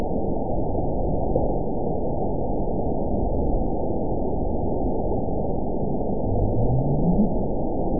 event 912433 date 03/26/22 time 20:49:05 GMT (3 years, 1 month ago) score 9.60 location TSS-AB04 detected by nrw target species NRW annotations +NRW Spectrogram: Frequency (kHz) vs. Time (s) audio not available .wav